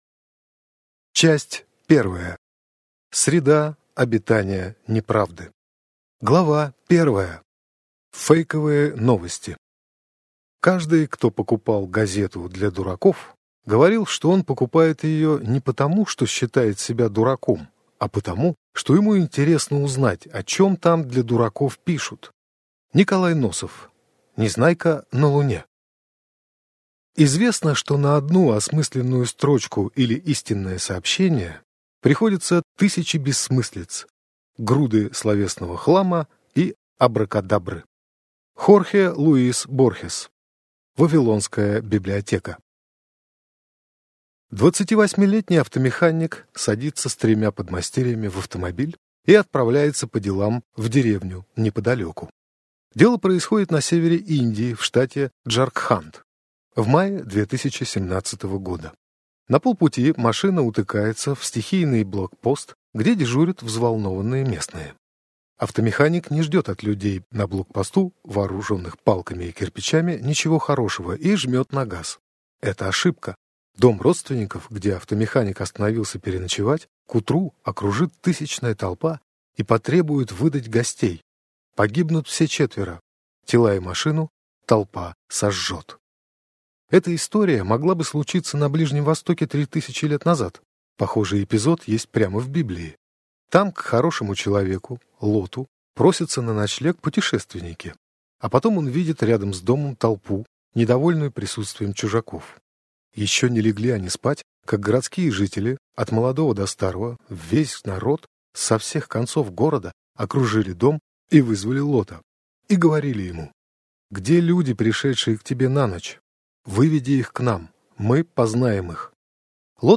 Аудиокнига Максимальный репост: Как соцсети заставляют нас верить фейковым новостям | Библиотека аудиокниг